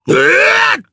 Phillop-Vox_Attack3_jp.wav